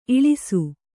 ♪ iḷisu